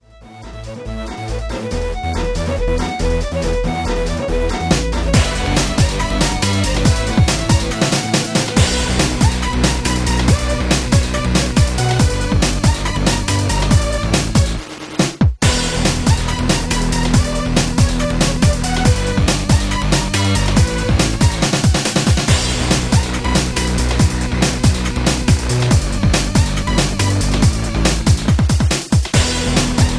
MPEG 1 Layer 3 (Stereo)
Backing track Karaoke
Pop, 2000s